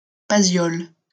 Paziols (French pronunciation: [pazjɔl]